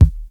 Kicks
JayDeeKick24.wav